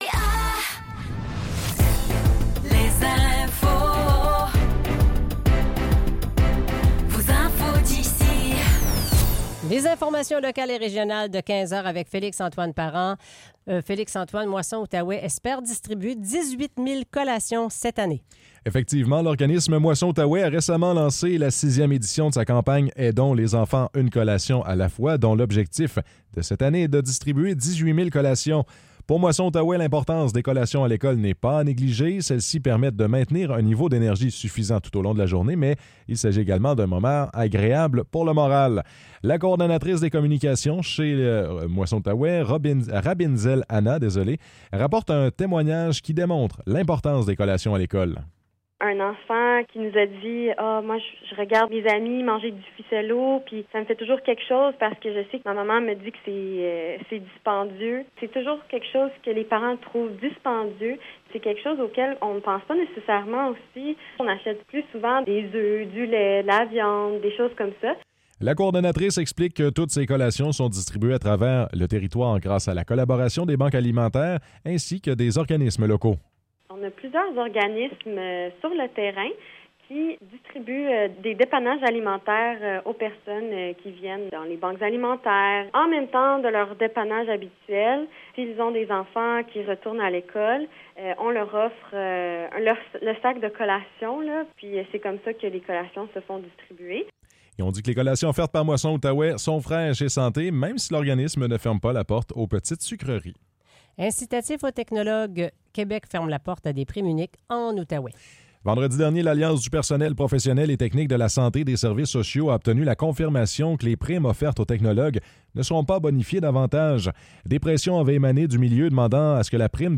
Nouvelles locales - 4 septembre 2024 - 15 h